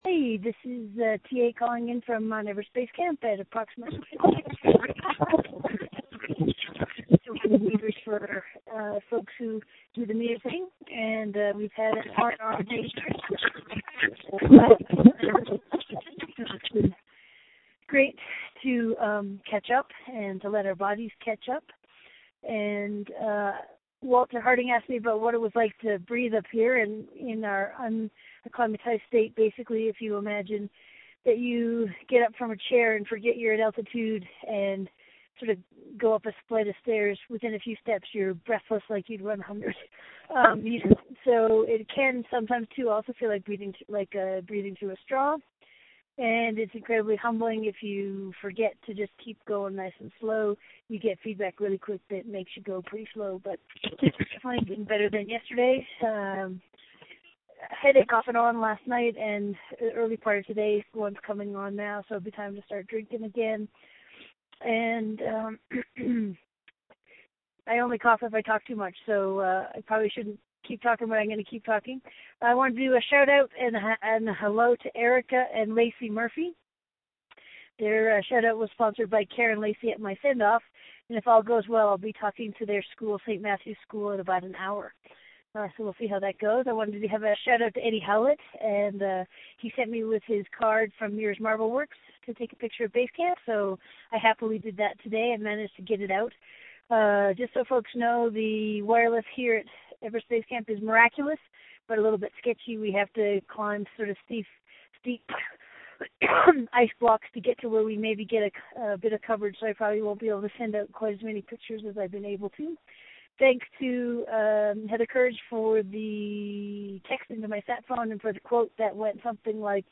Audio Post Everest Base Camp – Everest 3.0 Day 14